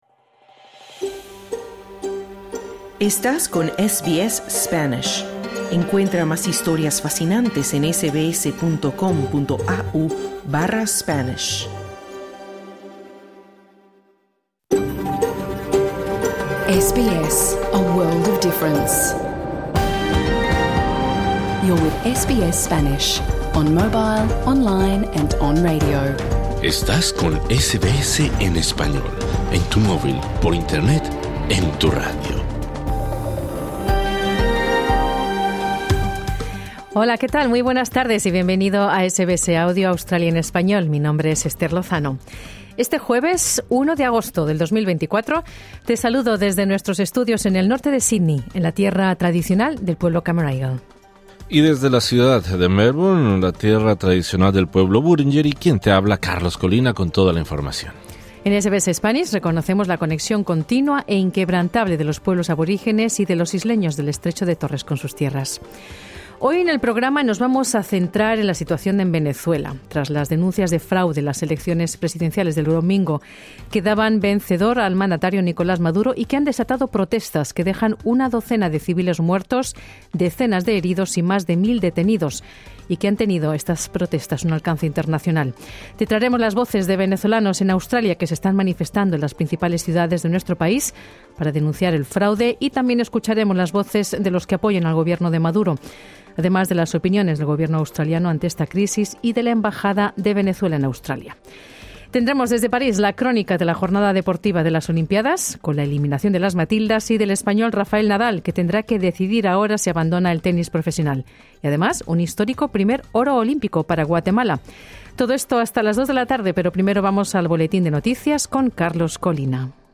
También escuchamos las distintas voces de la comunidad venezolana en Australia. Además, desde París, te traemos la crónica de la jornada deportiva de las olimpiadas con la eliminación de las Matildas y del español Rafael Nadal.